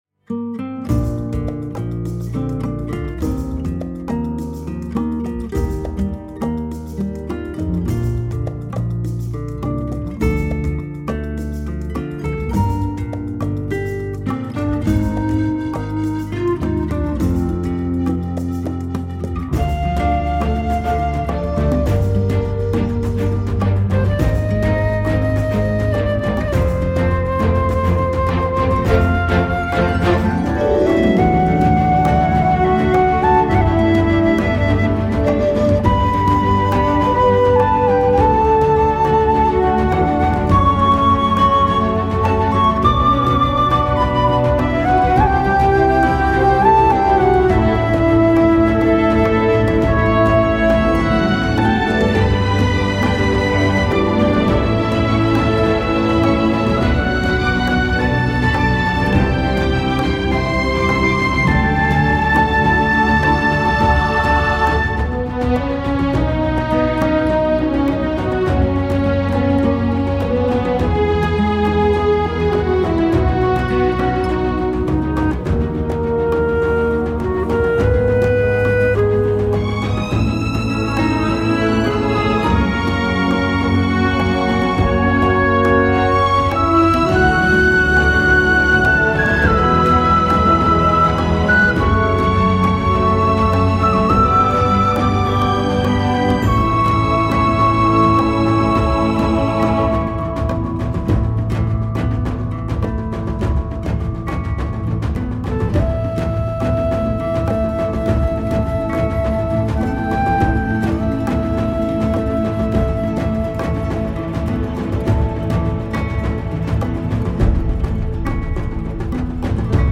bruitages percussifs pour faire forêt amazonienne